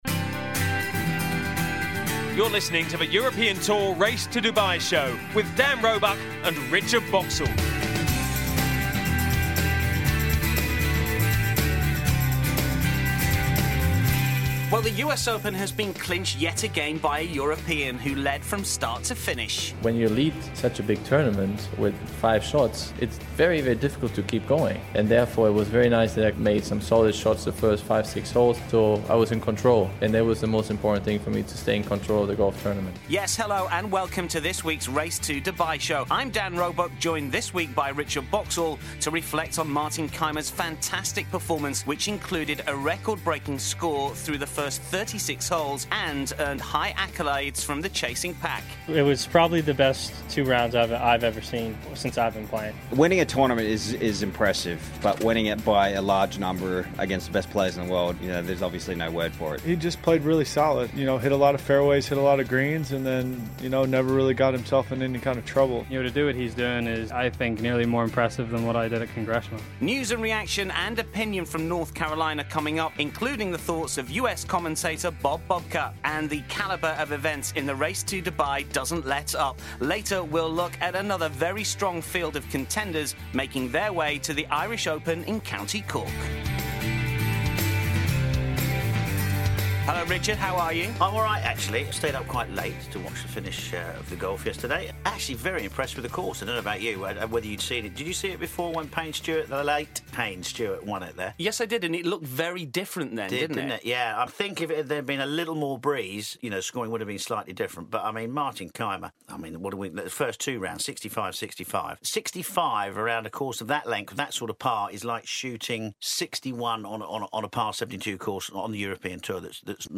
We also hear the thoughts of several other Golfers who competed at Pinehurst, including Erik Compton, Rickie Fowler, Rory McIlroy and Phil Mickelson. Plus there’s a preview of the next event in the Race to Dubai – The Irish Open